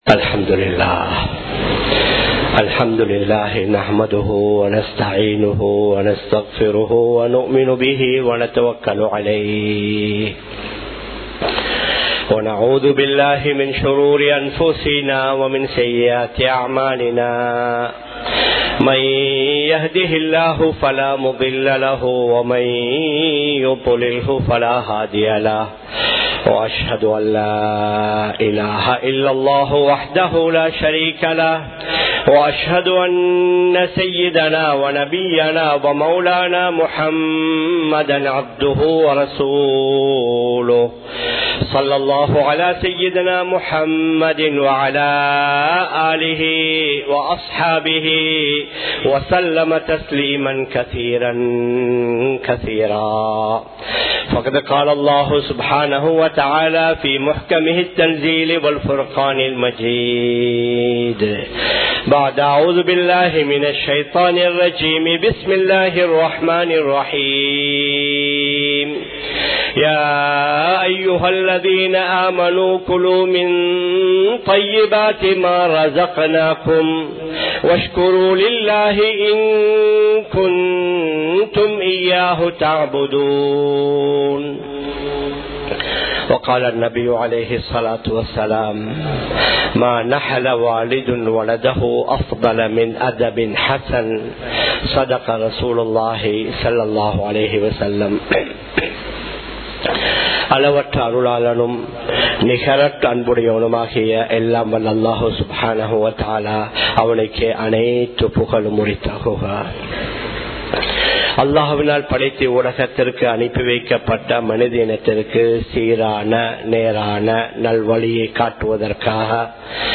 சிறந்த குழந்தை வளர்ப்பு | Audio Bayans | All Ceylon Muslim Youth Community | Addalaichenai
Muhiyadeen Jumua Masjith